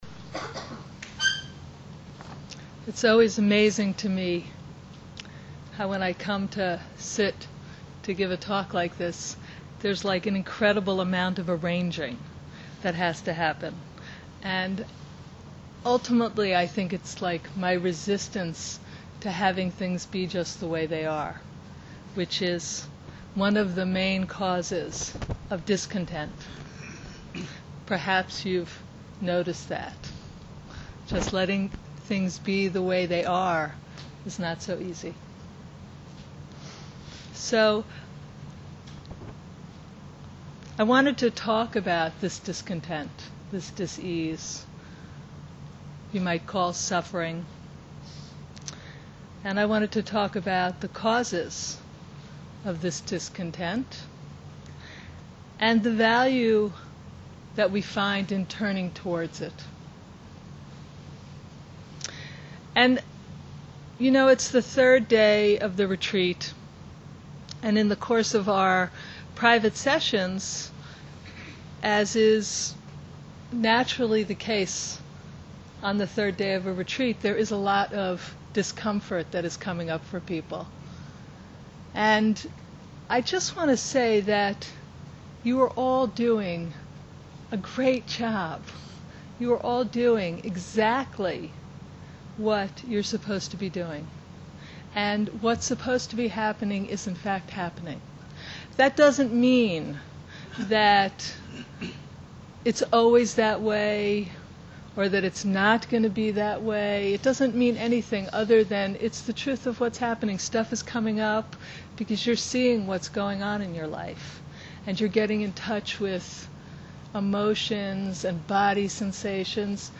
Jewish Meditation Lecture III
We are pleased to present the third in a series of four lectures given at the Jewish meditation retreat held at Kibbutz Hannaton in the Galil, March 11-17, 2012.